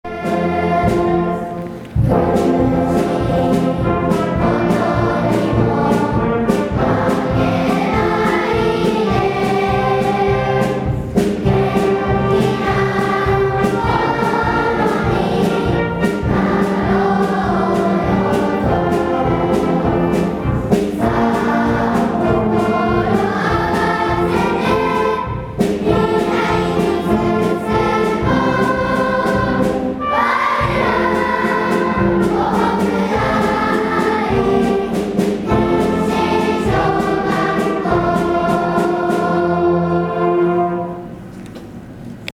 ６年生を含め，全校で歌う最後の校歌
校歌斉唱.MP3